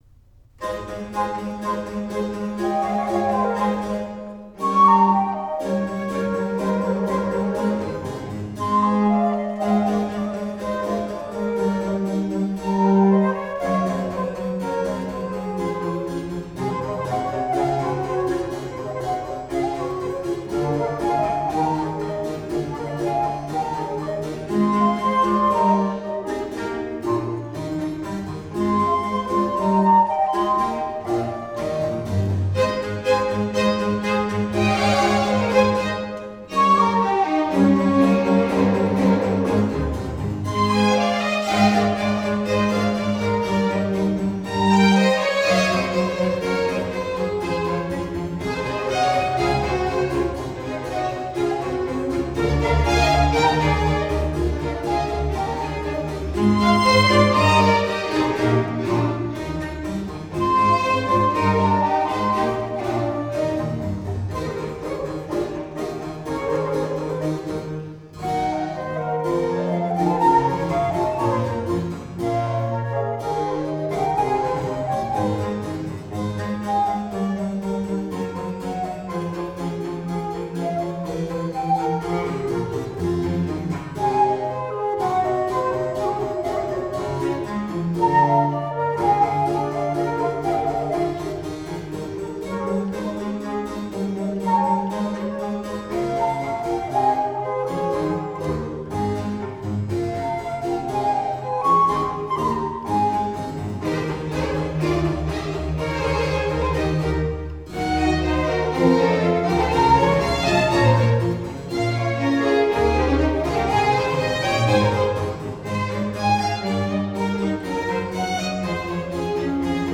A Corte Musical.